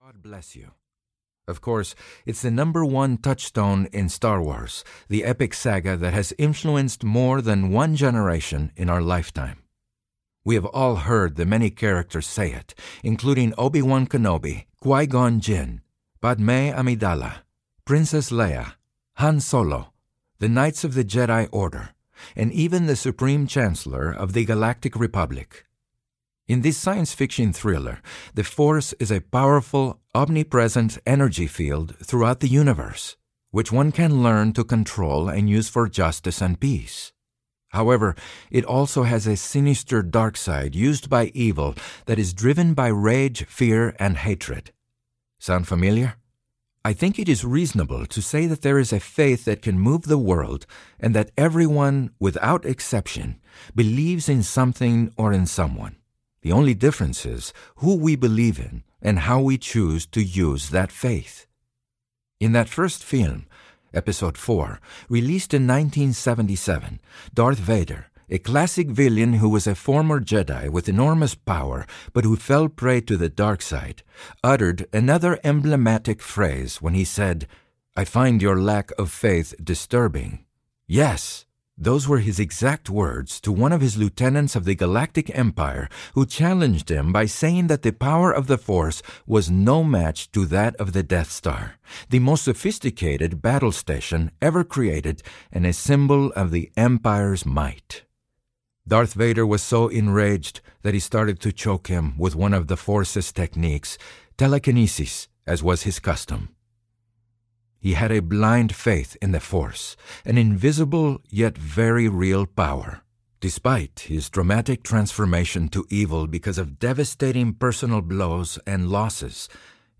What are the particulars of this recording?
7.25 Hrs. – Unabridged